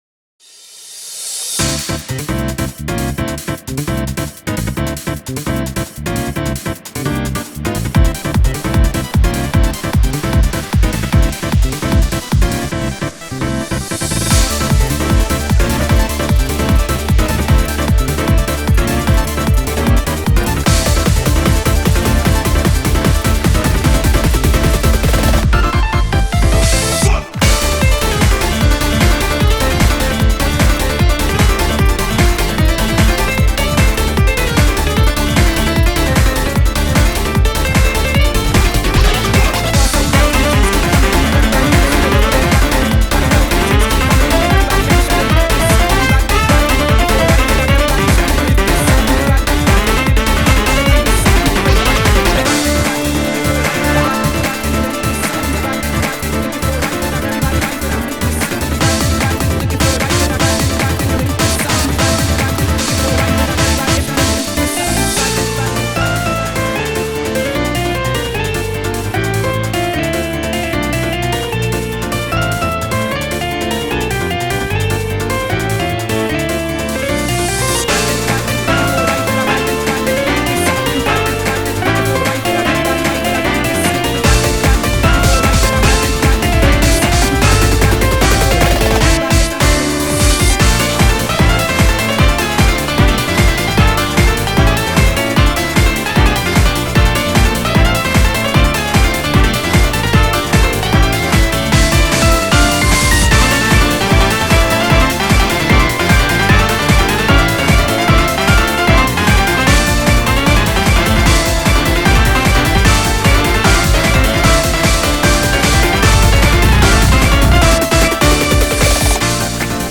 BPM151
Audio QualityPerfect (High Quality)
Comments[JAZZ-FUNK]